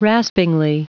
Prononciation du mot raspingly en anglais (fichier audio)
Prononciation du mot : raspingly